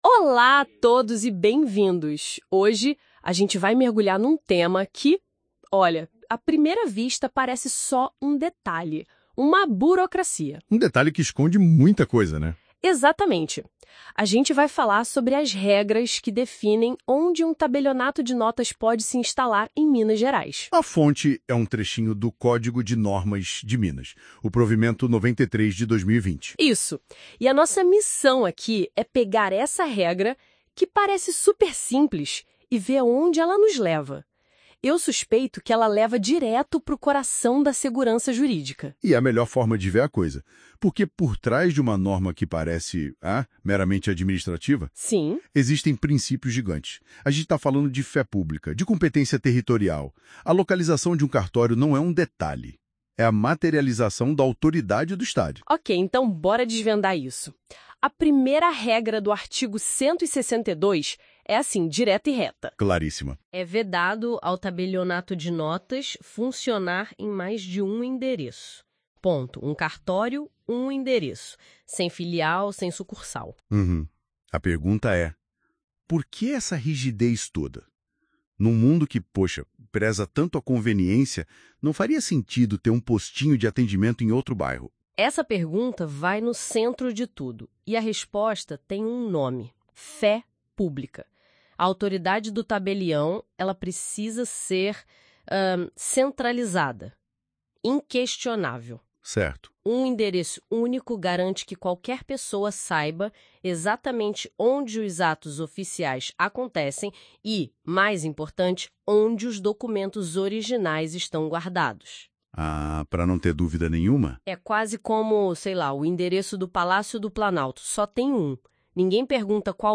Código de Normas MG – Livro II – Tabelionato de Notas (Áudio): 24 Aulas em Podcast: Conteúdo narrado com precisão (IA), facilitando a memorização da letra da lei e dos ritos notariais.